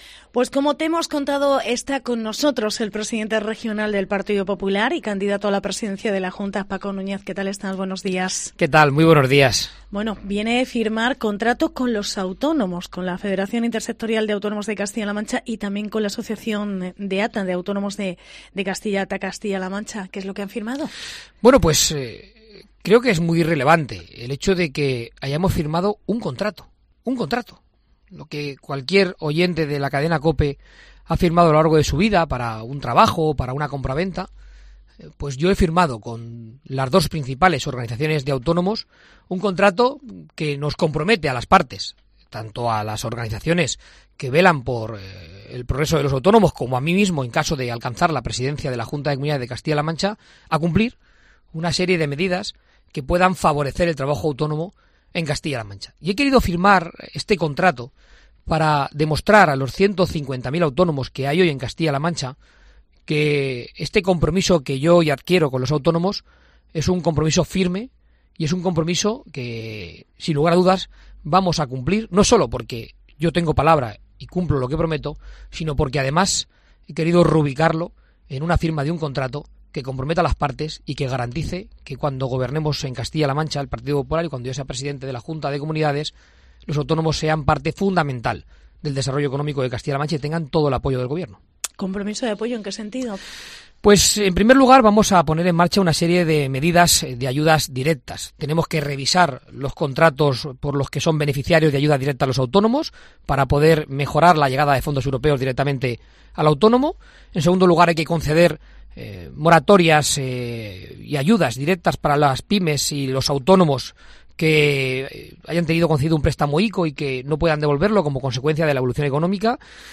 Paco Nuñez, presidente regional del PP en Herrera en COPE Castilla la Mancha